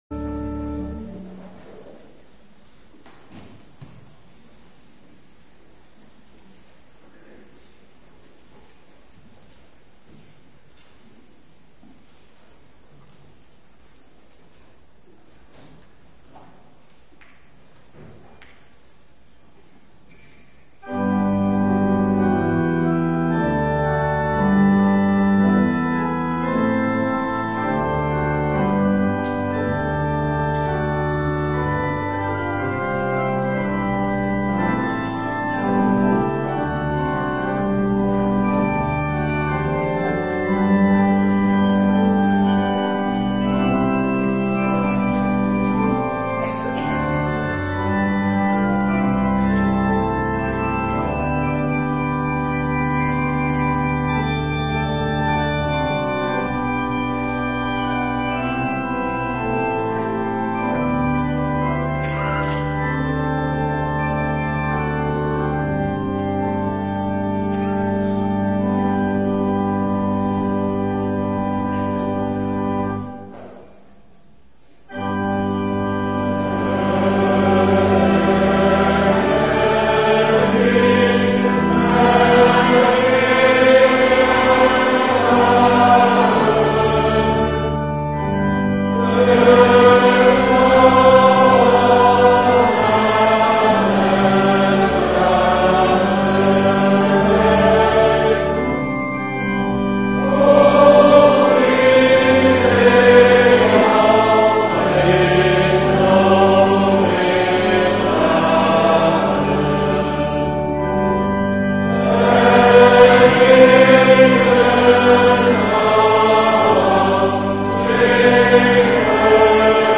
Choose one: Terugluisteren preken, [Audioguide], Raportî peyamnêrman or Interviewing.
Terugluisteren preken